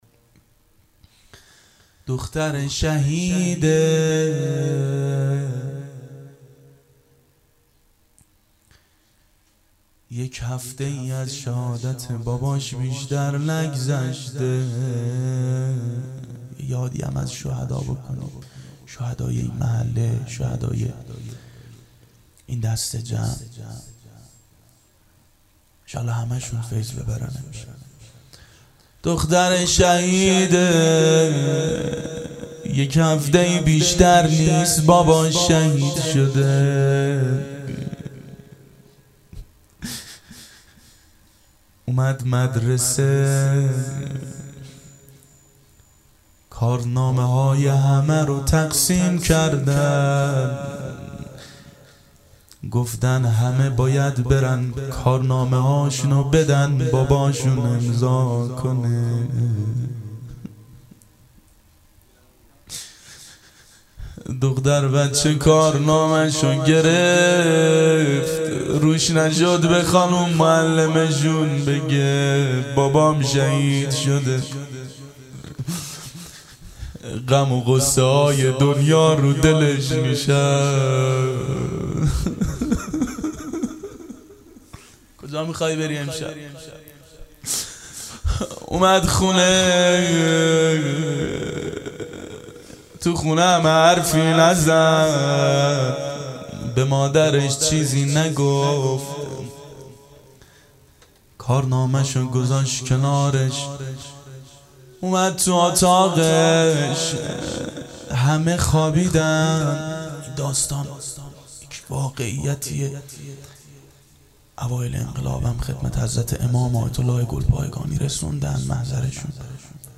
روضه حضرت رقیه سلام الله علیها
دهه اول صفر سال 1390 هیئت شیفتگان حضرت رقیه س شب دوم (شب شهادت)
02-روضه-حضرت-رقیه-س.mp3